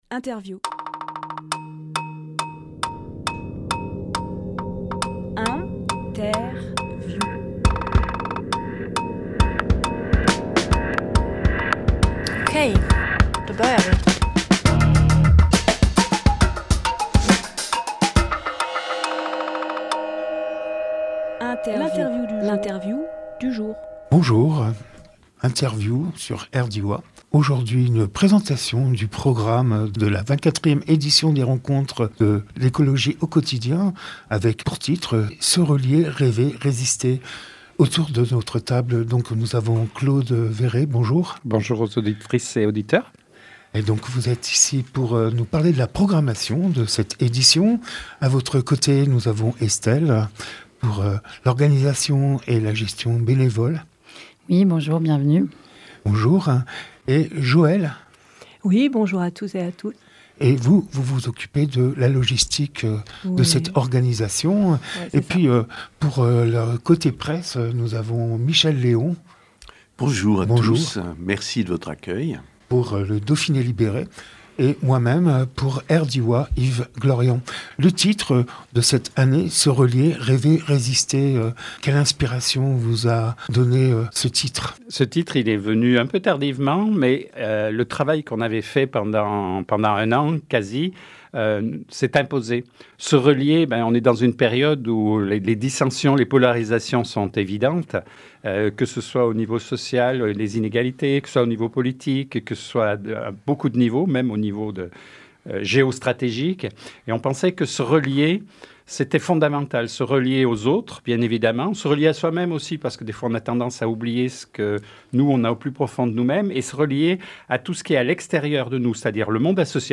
Emission - Interview Se relier, rêver, résister aux rencontres de l’écologie Publié le 22 décembre 2025 Partager sur…
lieu : Studio RDWA